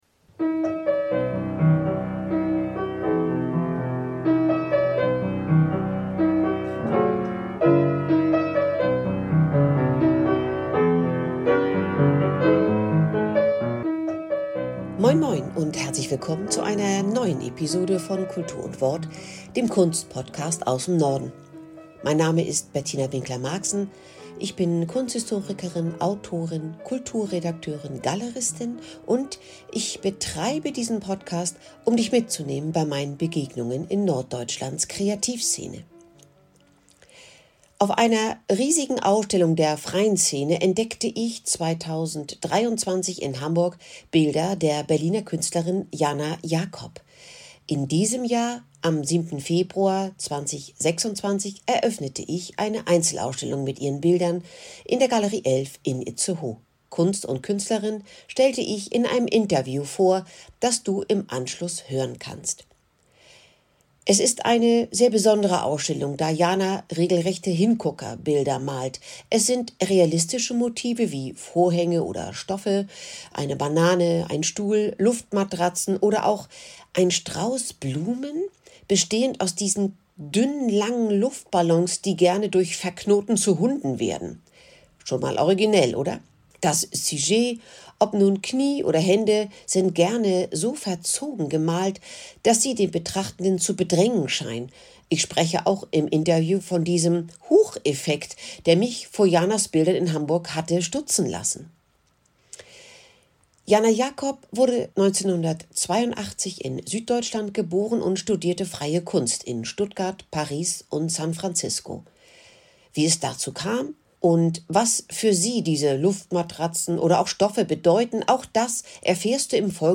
Wie es dazu kam und was für sie diese Luftmatratzen oder auch Stoffe bedeuten, auch das erfährst du in diesem Interview.